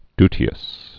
(dtē-əs, dy-)